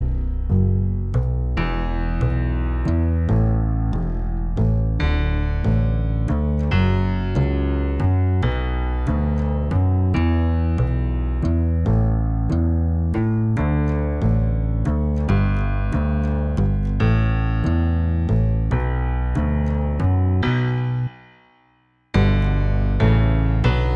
Minus Guitars Pop (1970s) 4:58 Buy £1.50